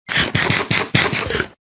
Minecraft Eating Sound Button - Botão de Efeito Sonoro